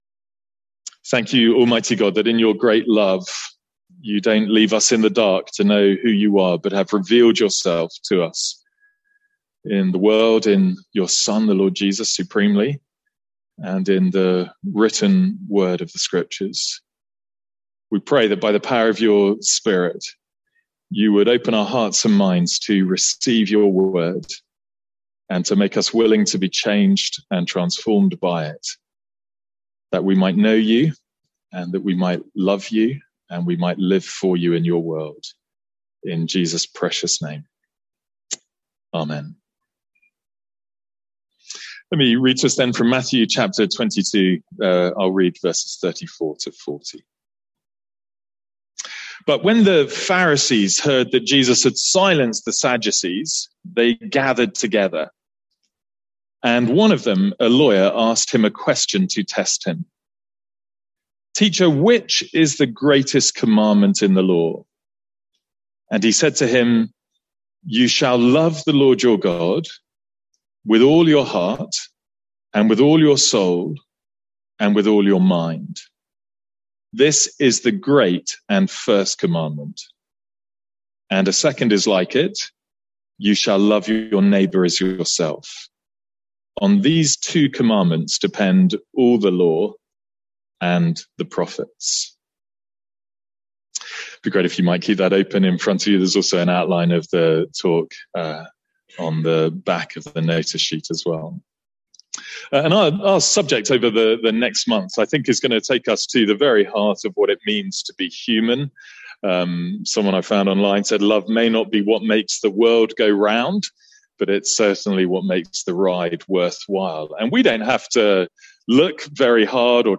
Sermons | St Andrews Free Church
From our morning service on Loving God.